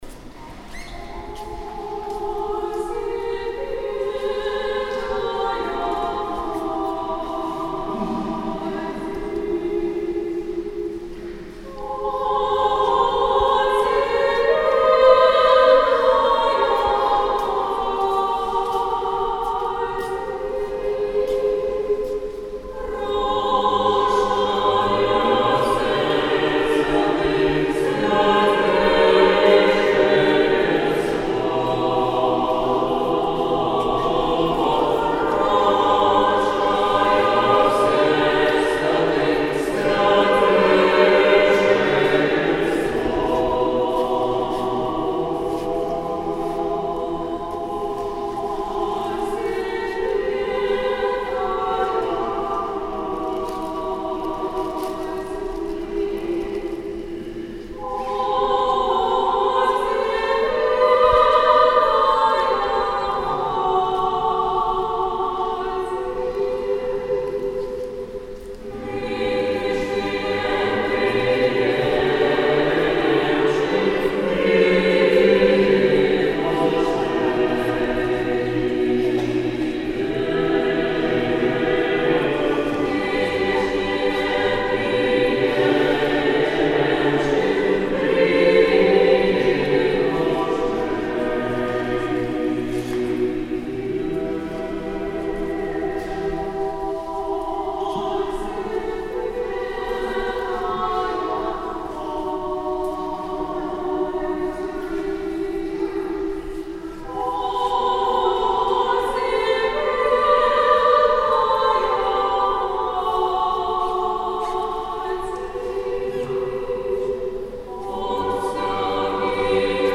Архиерейский хор кафедрального собора.